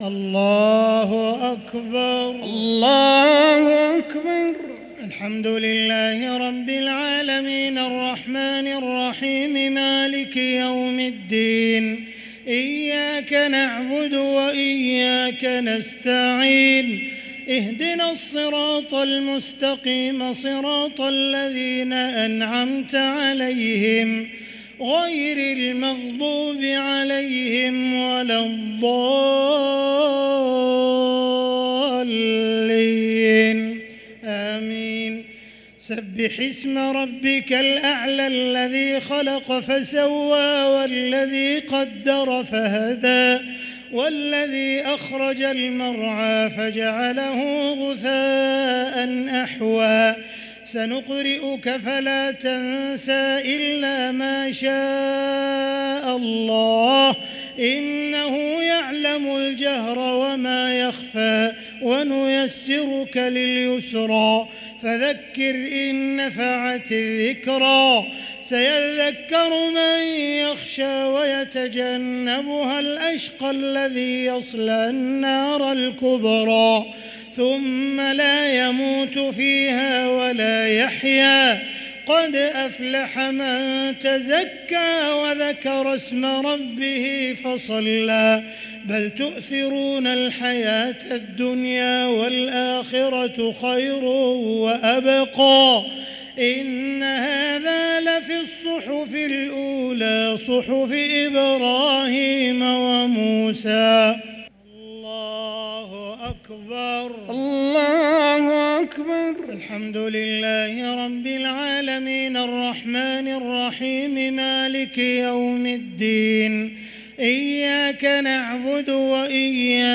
صلاة التراويح ليلة 13 رمضان 1444 للقارئ عبدالرحمن السديس - الشفع والوتر - صلاة التراويح